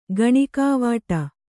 ♪ gaṇikā vāṭa